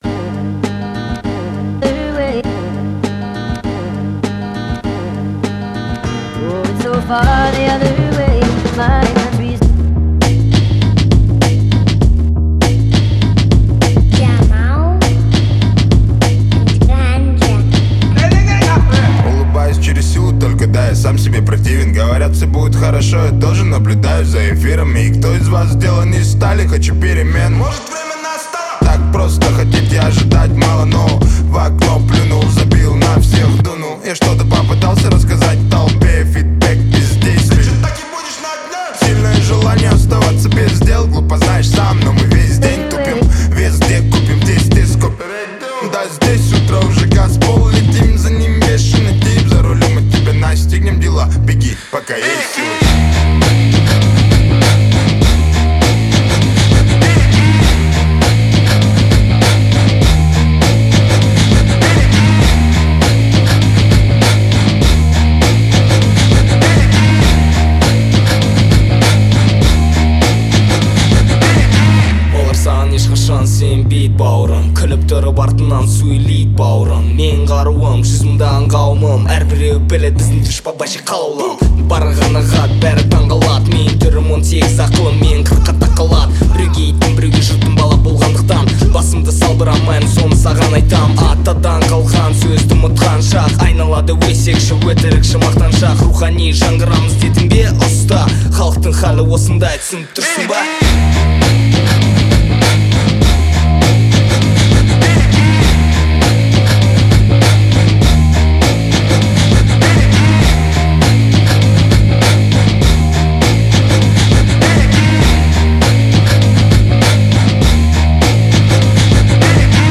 который сочетает в себе мощный вокал и эмоциональную подачу.